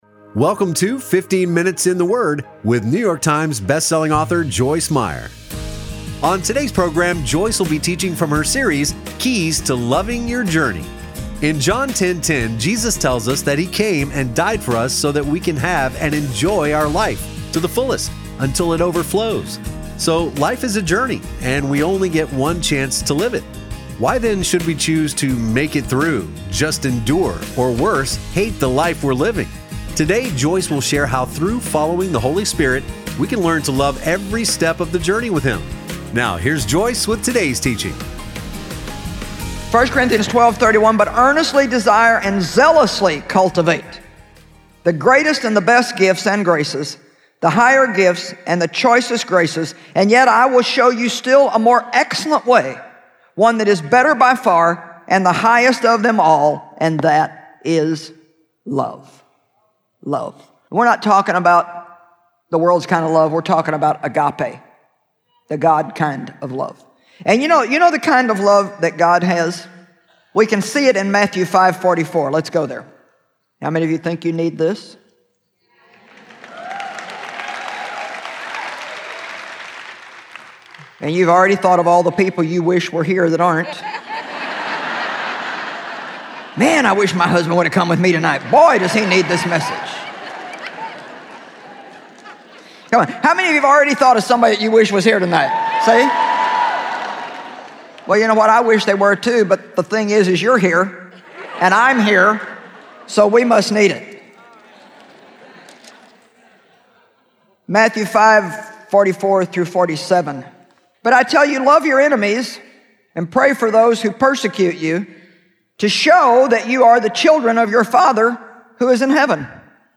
Joyce Meyer teaches on a number of topics with a particular focus on the mind, mouth, moods and attitudes. Her candid communication style allows her to share openly and practically about her experiences so others can apply what she has learned to their lives.